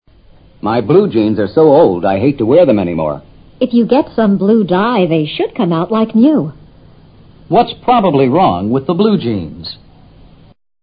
女方说找些染料染一下，裤子就会像新的一样。